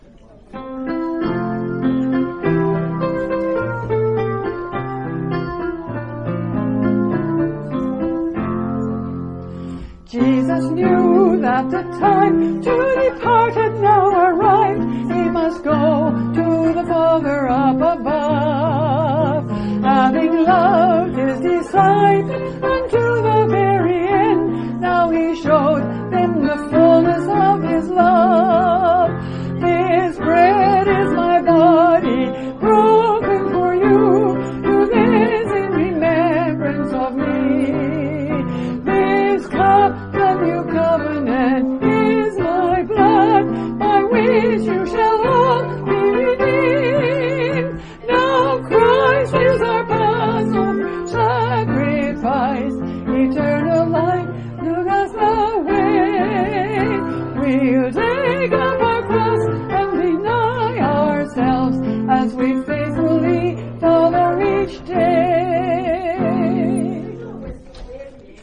Hymn-2.mp3